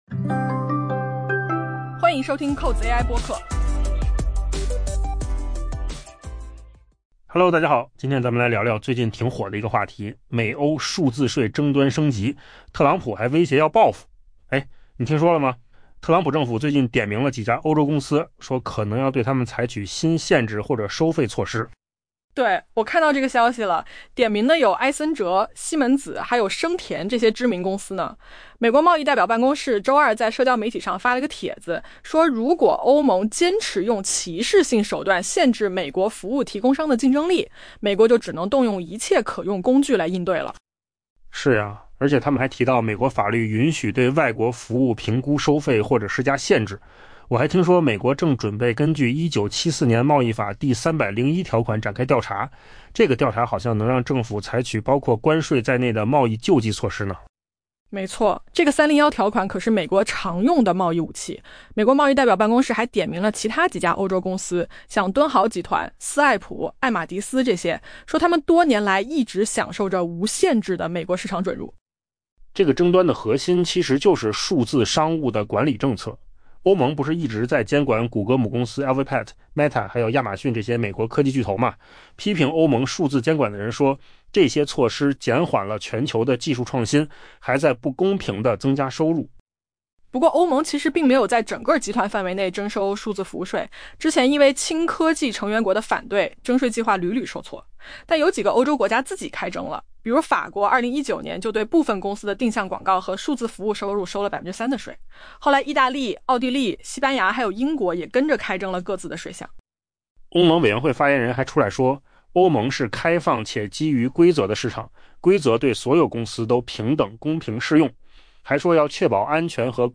AI 播客：换个方式听新闻 下载 mp3 音频由扣子空间生成 特朗普政府威胁对欧盟进行报复，以回应其向美国科技公司征税的努力，并点名埃森哲 （Accenture Plc）、西门子 （Siemens AG） 和声田 （Spotify Technology SA） 等知名公司，称其可能成为新限制或收费的目标。